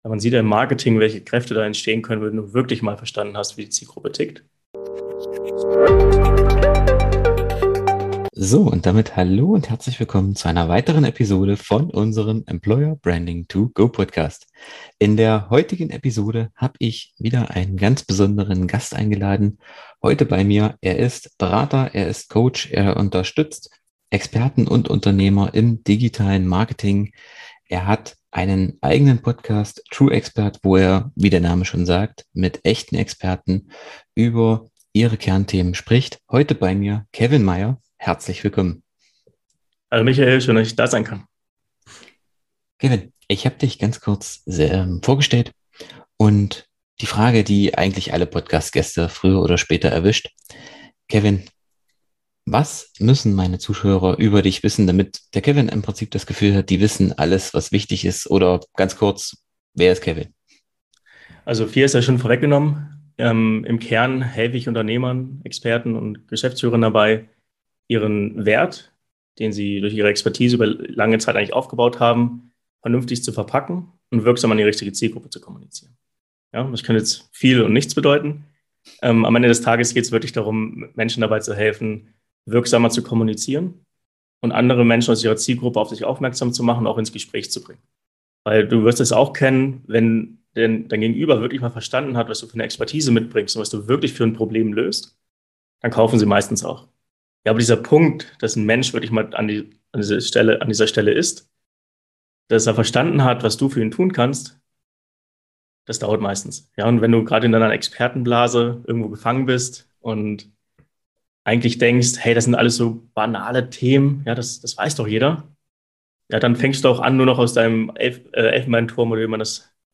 Folge 92: Mitarbeiter gewinnen durch wertorientierte Positionierung - Interview